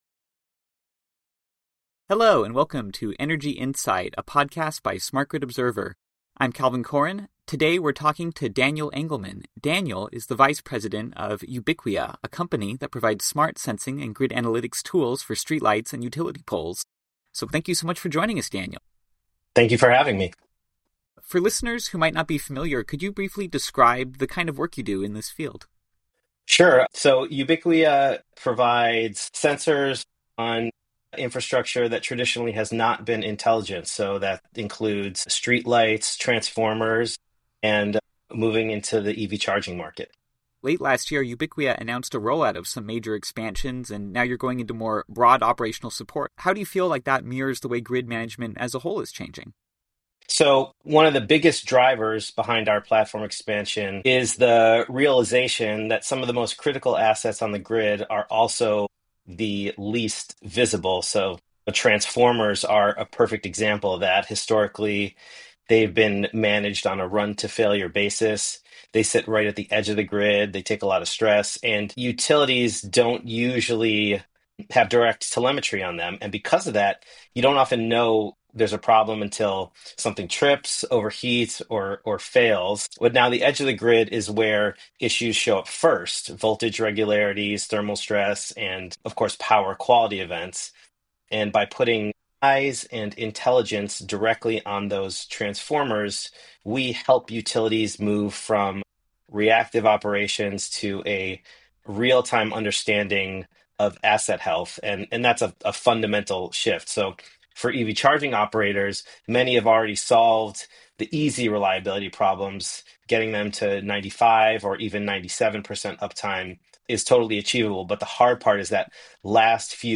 Edge Intelligence and Improving EV Charging Uptime: An Interview with Ubicquia